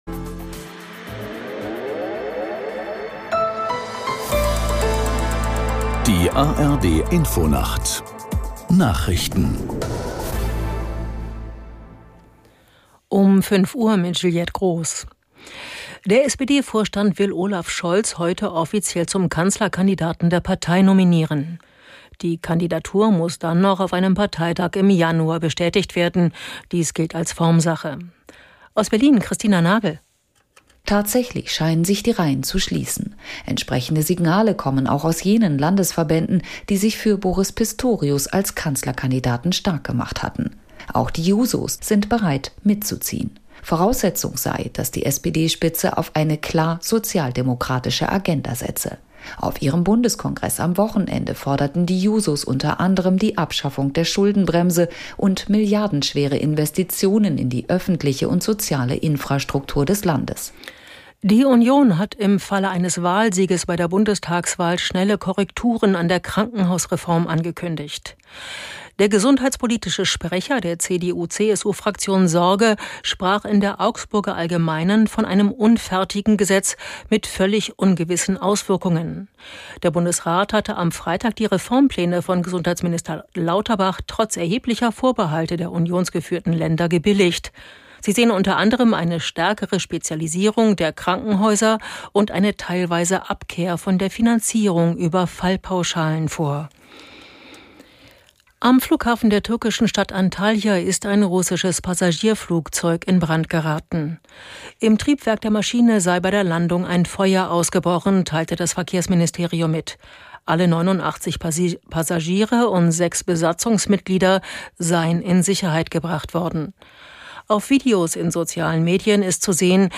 Alle 30 Minuten die aktuellen Meldungen aus der NDR Info Nachrichtenredaktion. Politik, Wirtschaft, Sport. 24 Stunden am Tag - 365 Tage im Jahr.